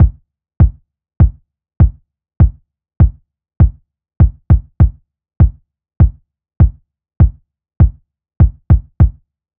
Unison Funk - 5 - 100bpm - Kick.wav